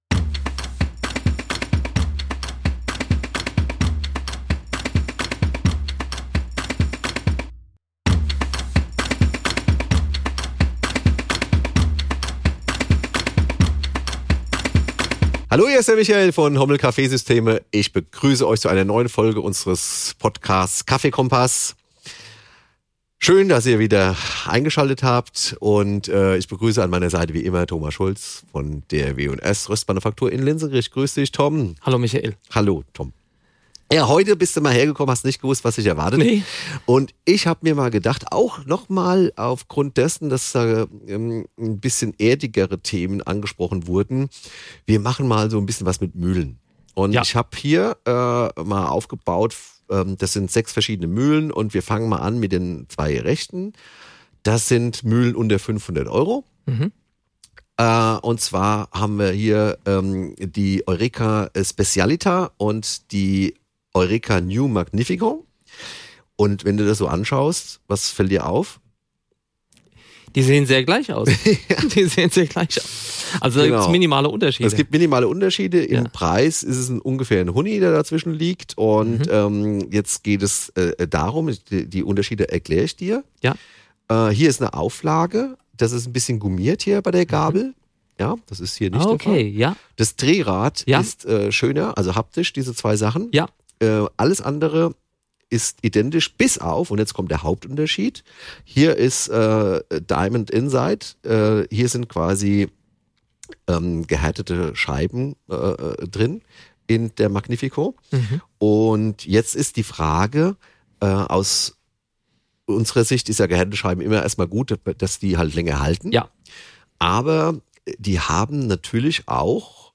Live Test | Mühlen unter 500 Euro | KaffeeKOMPASS überprüft | Folge 73 ~ KaffeeKOMPASS Podcast